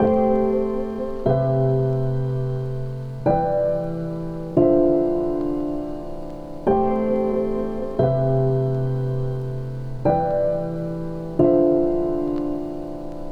Lofi Jazz Piano.wav